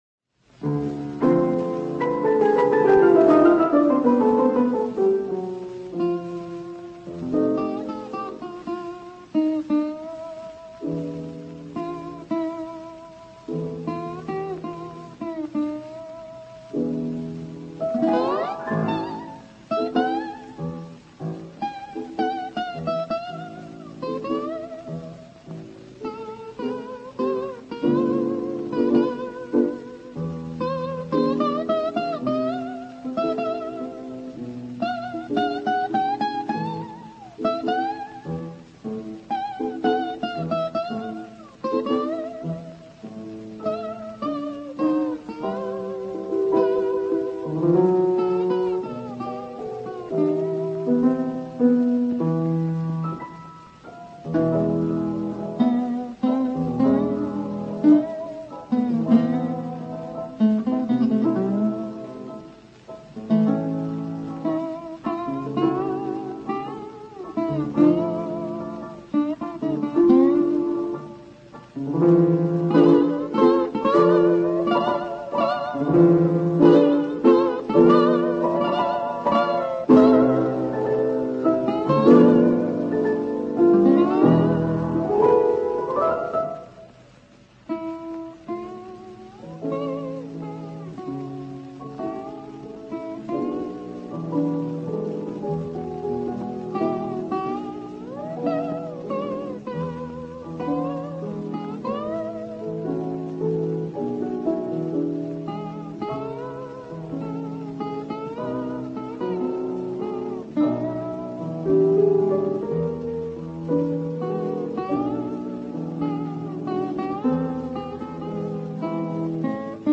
звучит гавайская гитара